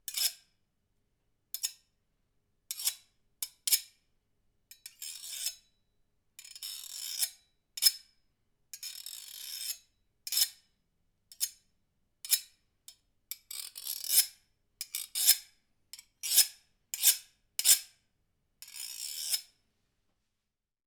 Kitchen Knife Scratches 3 Sound
household